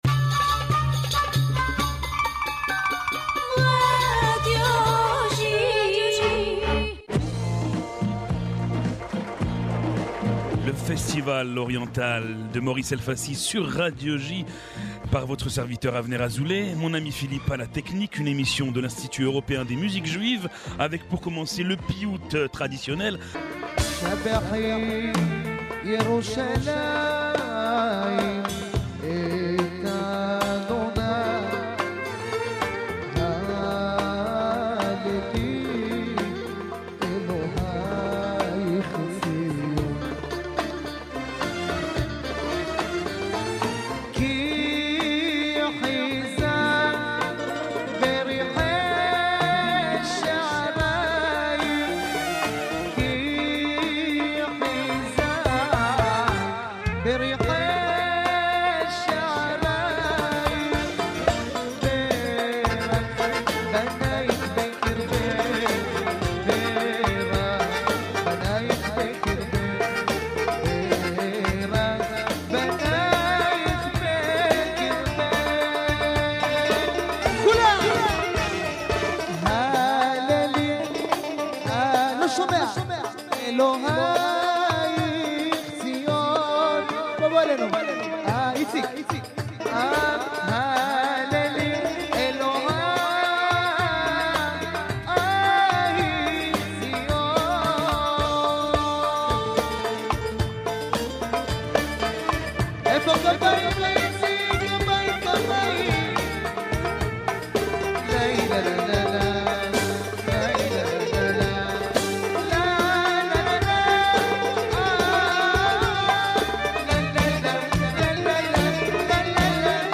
« The Oriental festival » is a radio program from the European Institute of Jewish Music entirely dedicated to Eastern Music.